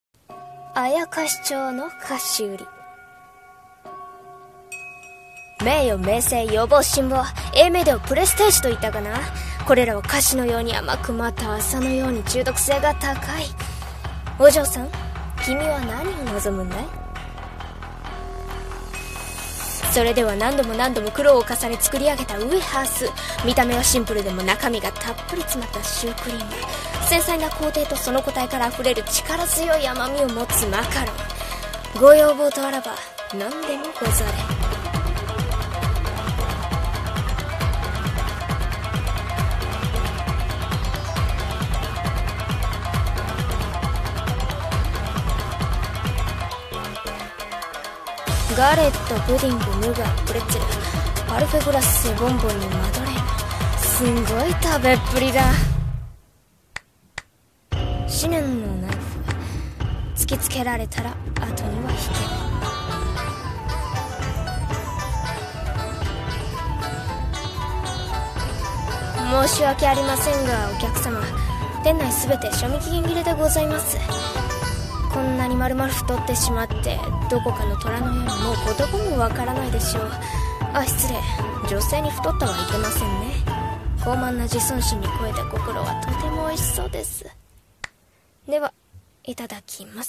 CM風声劇「妖町の菓子売」